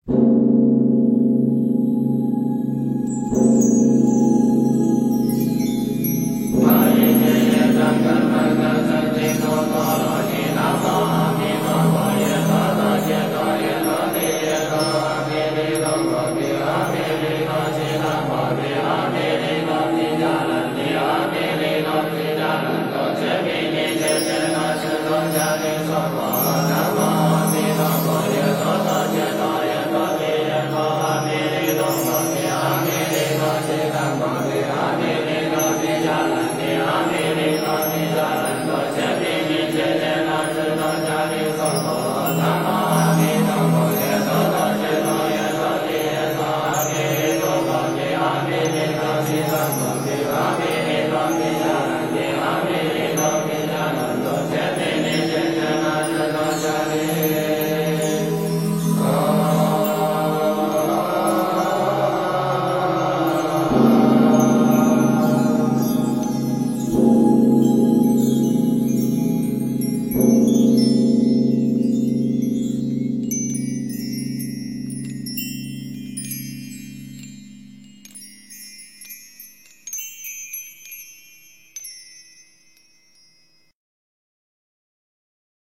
往生咒 - 诵经 - 云佛论坛
佛音 诵经 佛教音乐 返回列表 上一篇： 三皈依 下一篇： 心经 相关文章 禅悟--佛教音乐 禅悟--佛教音乐...